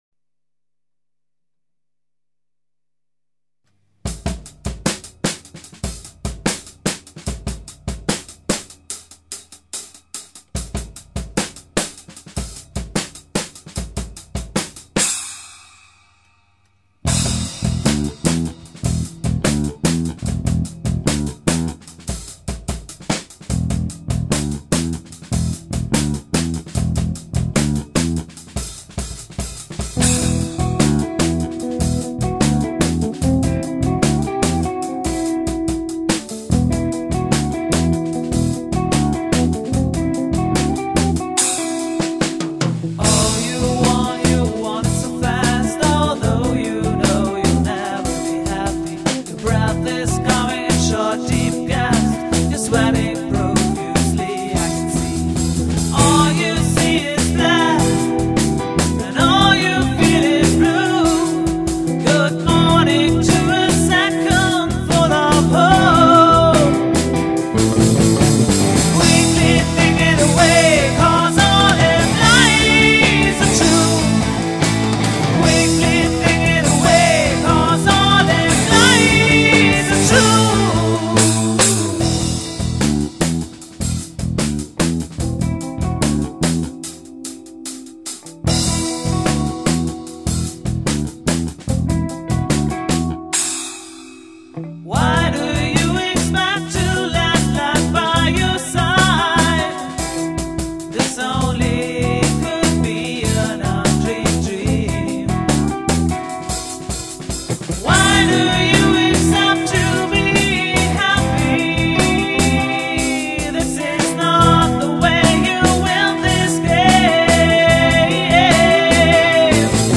Etwas publikumstauglicher als die anderen Sachen, die ich Euch bislang vorgestellt habe...
Die "normale" Band nennt sich "Creep"... das Stück nennt sich "Fear of flying". Auch dieses Stück haben wir im Proberaum mitgeschnitten...